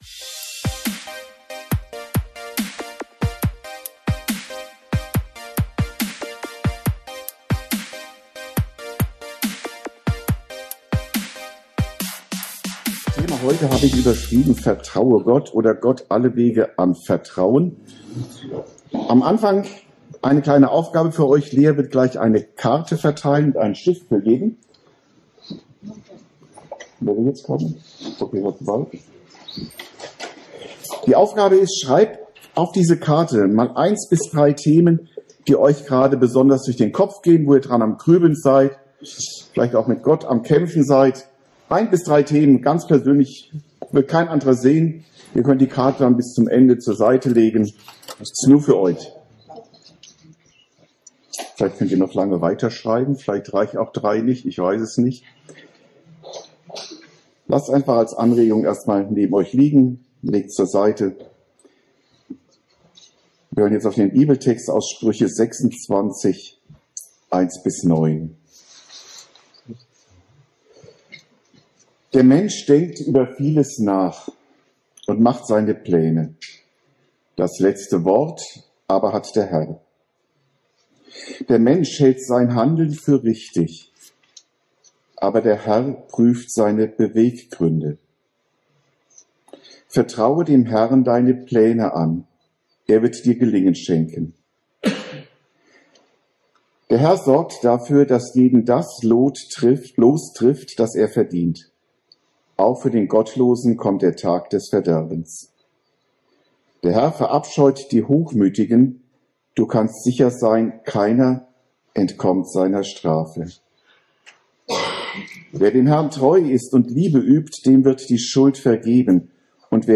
Predigten u. Andachten (Live und Studioaufnahmen ERF)